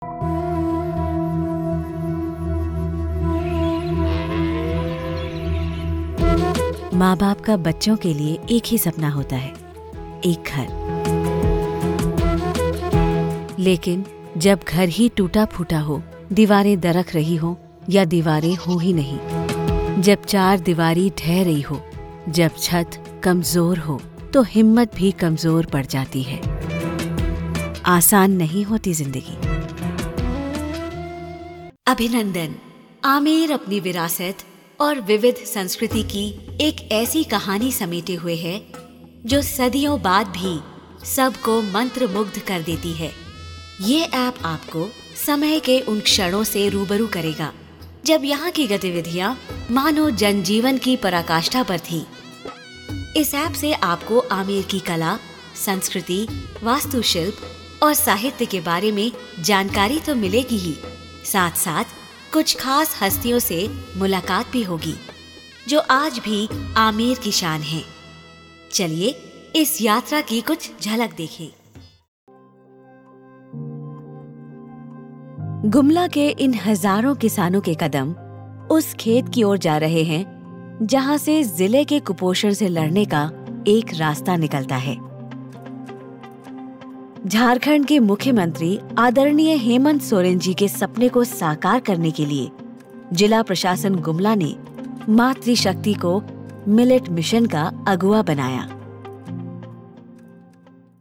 Jong, Natuurlijk, Veelzijdig, Vriendelijk, Warm
Audiogids